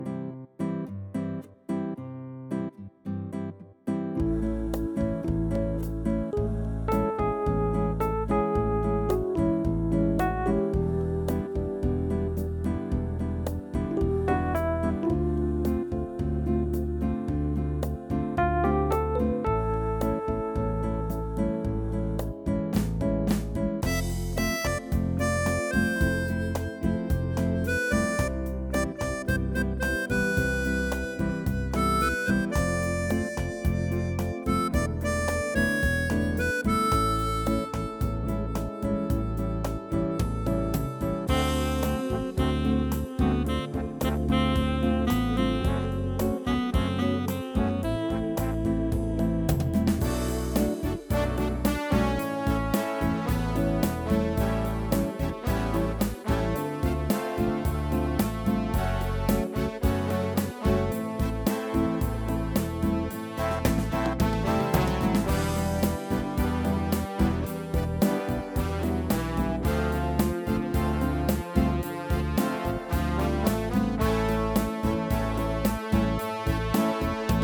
קטע נגינה חביב באורגן